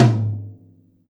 Index of /kb6/Akai_MPC500/1. Kits/Amb Rm Kit